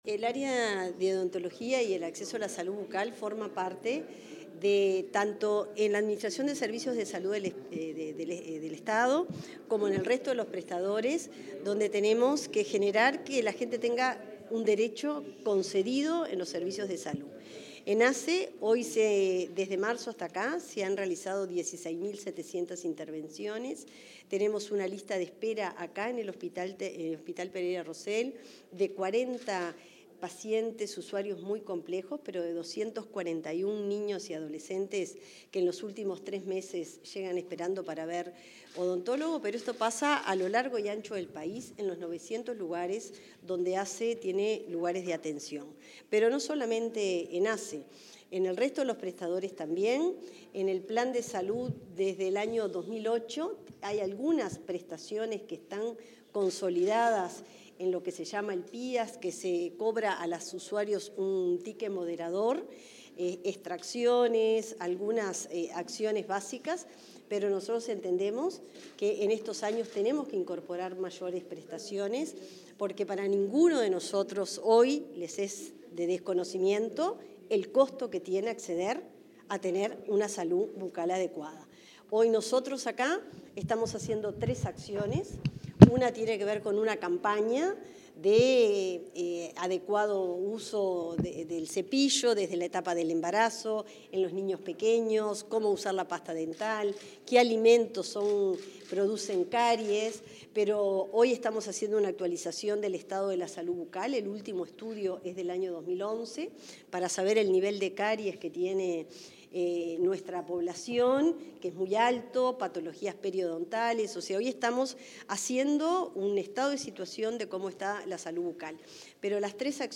En una rueda de prensa, la ministra de Salud Pública, Cristina Lustemberg, repasó las principales acciones desplegadas para garantizar el acceso de